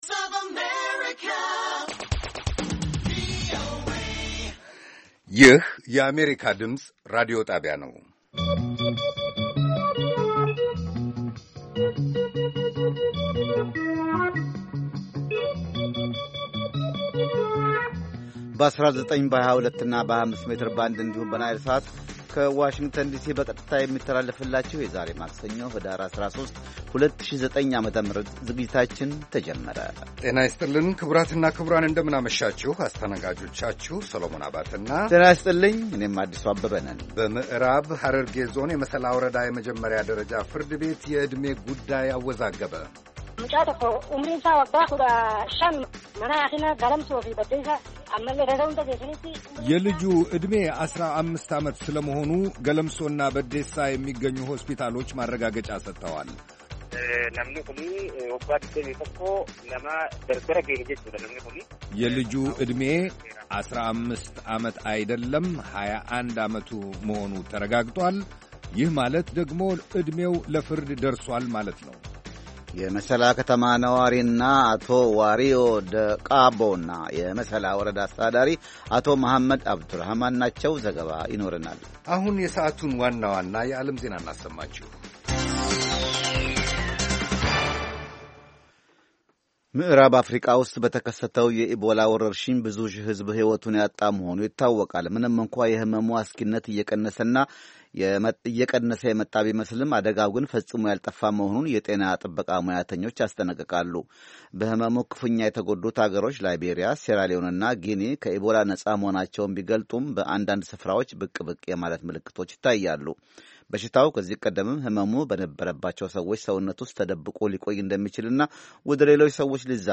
ከምሽቱ ሦስት ሰዓት የአማርኛ ዜና